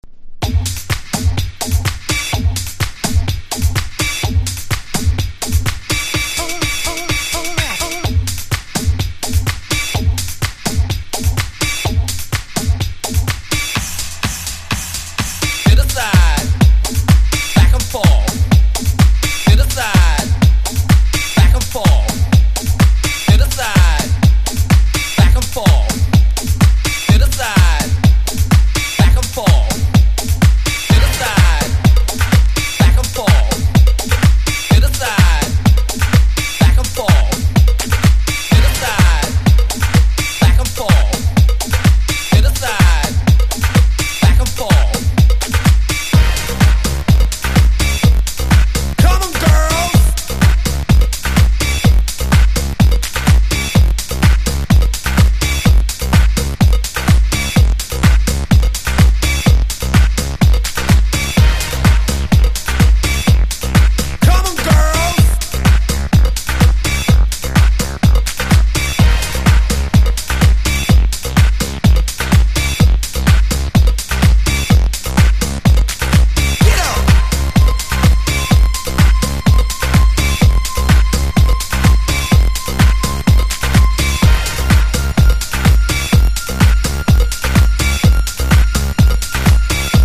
• HOUSE